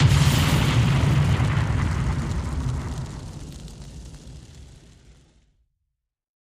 Single explosion with long ring out.